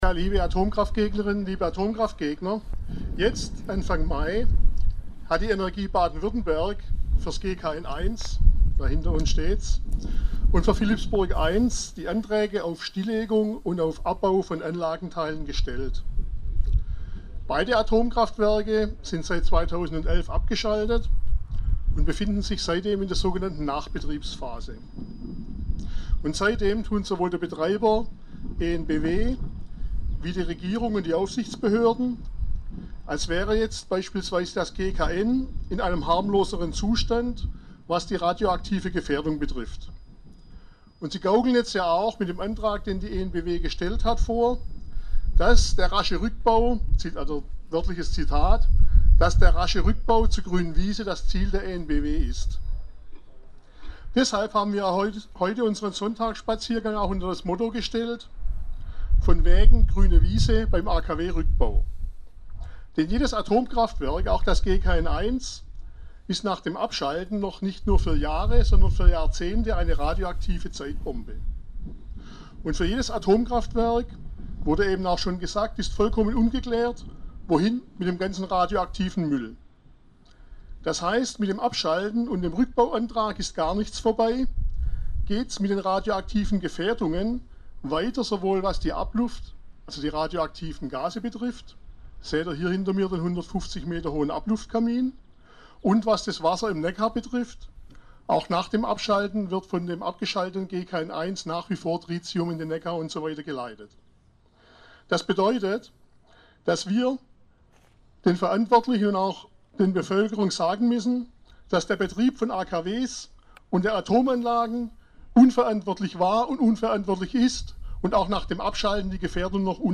Zum Nachhören: Rückblick Sonntagsspaziergang 02. Juni 13 Die EnBW hat Anfang April den ersten Antrag für den Beginn des offiziellen Rückbaus der Atomkraftwerke Neckarwestheim 1 und Philippsburg 1 gestellt.